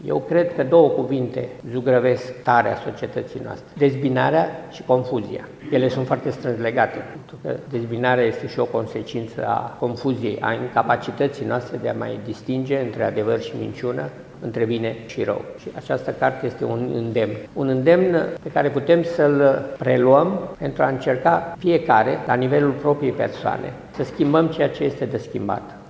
Universitatea de Medicină și Farmacie din Tîrgu-Mureș a găzduit azi evenimentul de lansare a cărții „Prețul demnității. O istorie altfel”, scrisă de Laura Ganea.
Emil Constantinescu a explicat cele două titluri ale cărții, subliniind că demnitatea nu este legată de o funcție, ci reprezintă o atitudine morală: